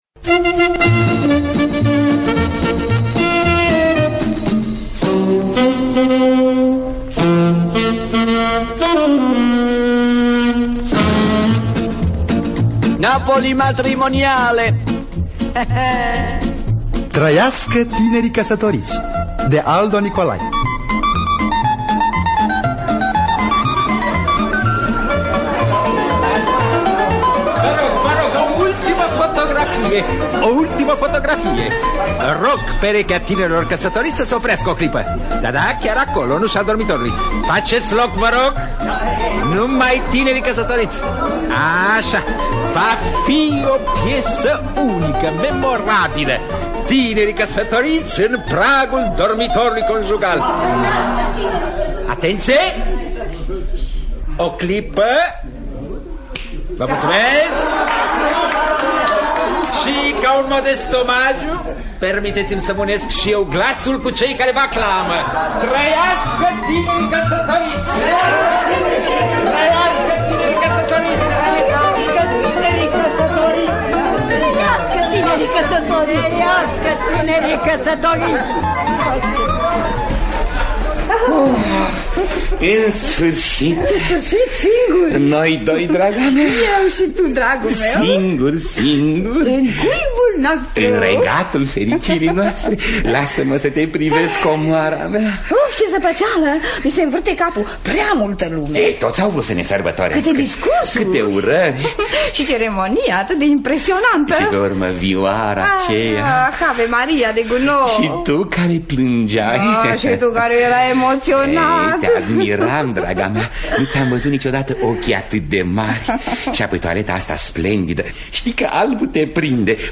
Trăiască tinerii căsătoriți de Aldo Nicolaj – Teatru Radiofonic Online
Ȋn distribuţie: Nineta Gusti, Ion Lucian.